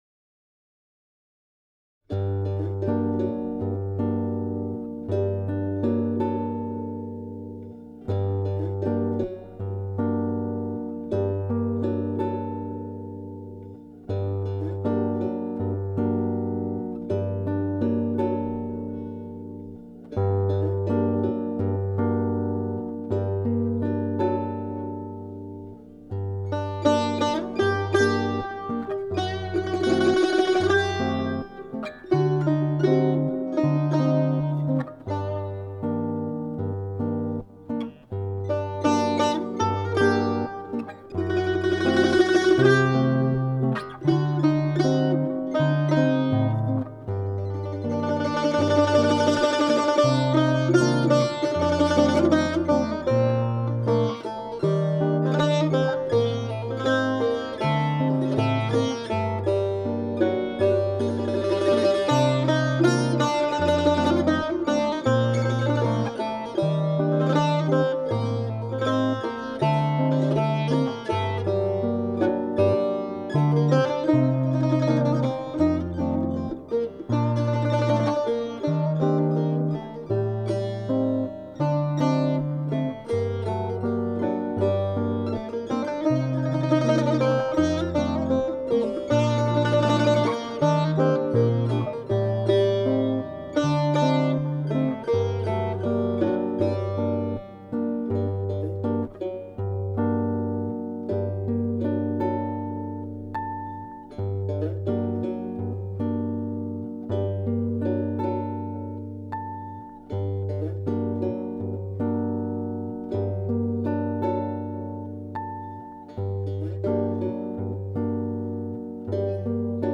Azeri Folk Song